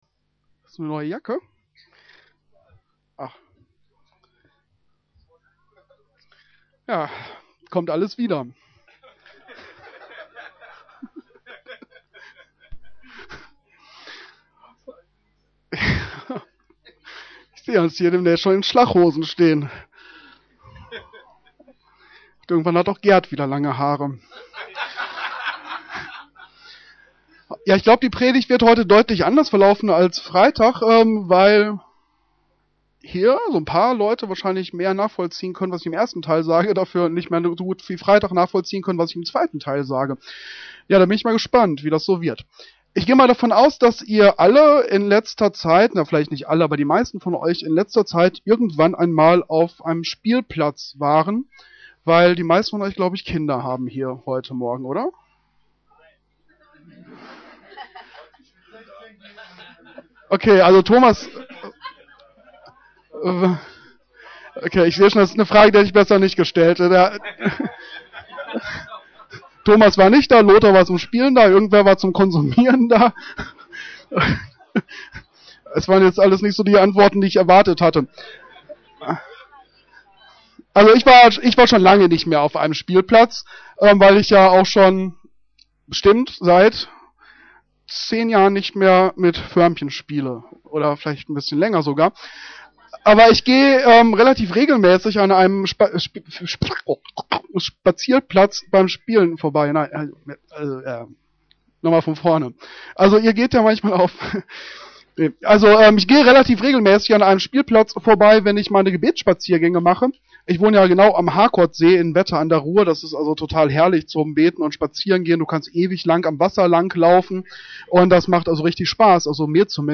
Auf dieser Seite findest du unsere Predigten der letzten 18 Jahre.